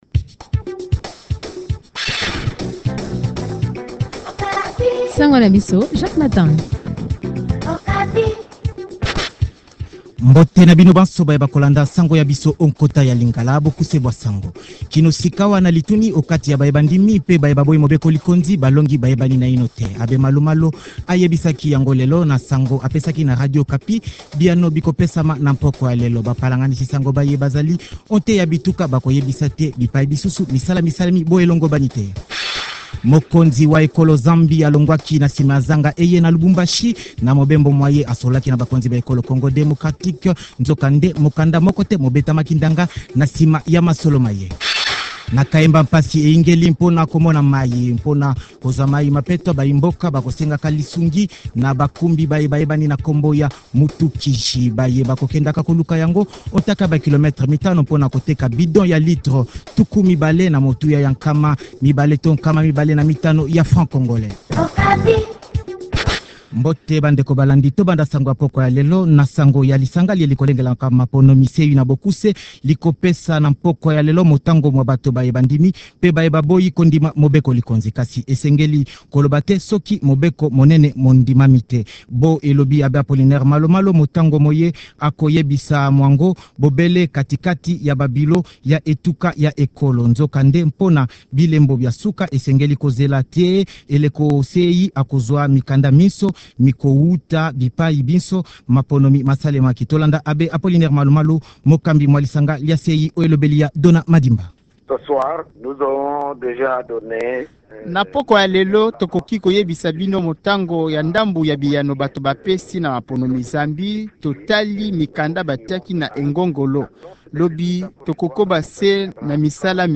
Journal Lingala